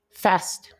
Ääntäminen
IPA : /fɛst/